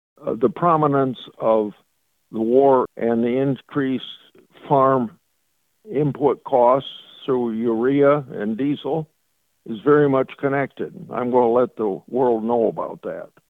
Senator Grassley made his comments on Tuesday during his weekly ag conference call with farm broadcasters and reporters.